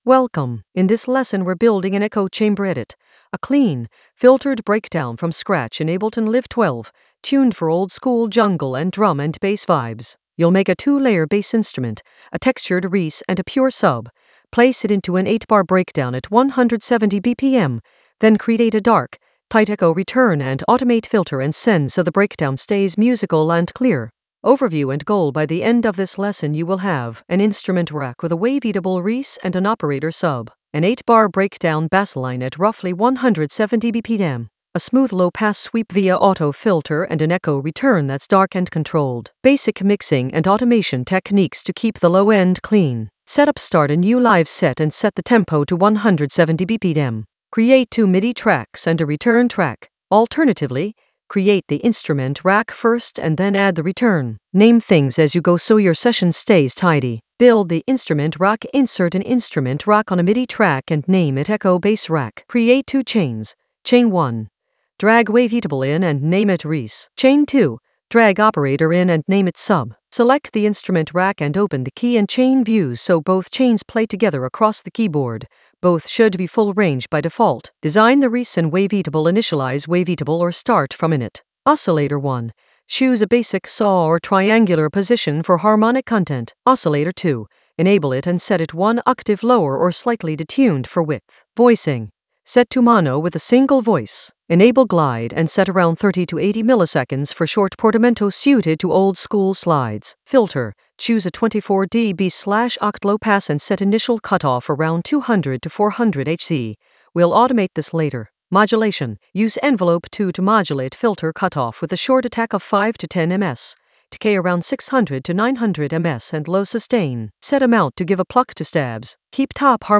An AI-generated beginner Ableton lesson focused on Echo Chamber edit: a filtered breakdown clean from scratch in Ableton Live 12 for jungle oldskool DnB vibes in the Basslines area of drum and bass production.
Narrated lesson audio
The voice track includes the tutorial plus extra teacher commentary.